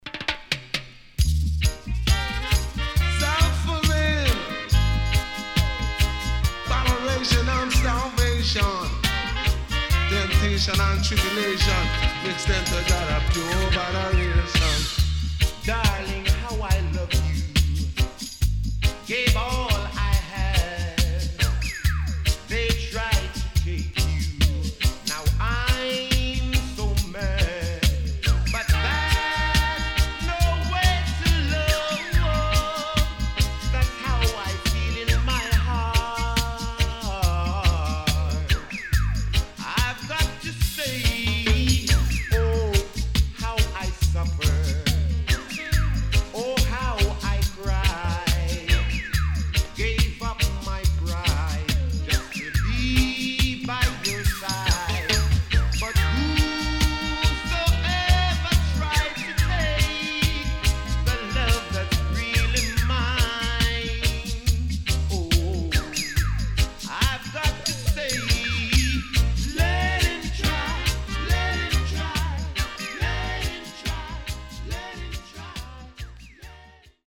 HOME > DISCO45 [VINTAGE]  >  70’s DEEJAY
CONDITION SIDE A:VG(OK)〜VG+
SIDE A:うすいこまかい傷ありますがノイズあまり目立ちません。